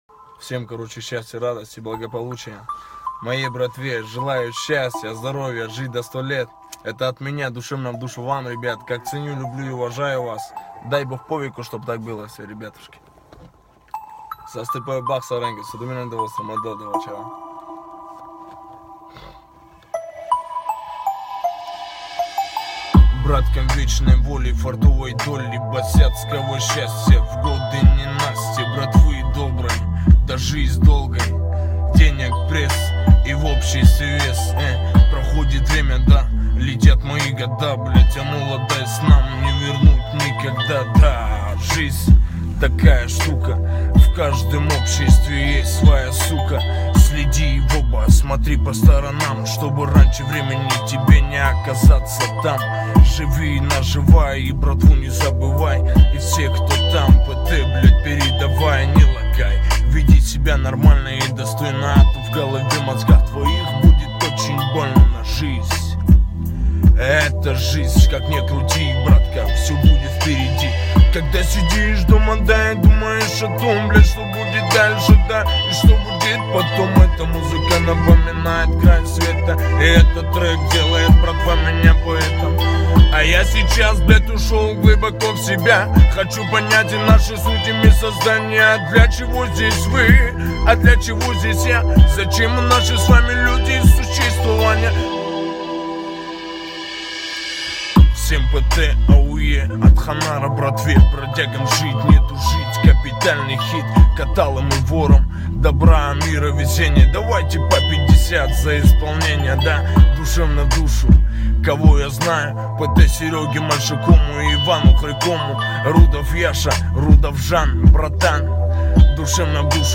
цыган_поет_фартовую_песню
cygan_poet_fartovujy_pesnjy.mp3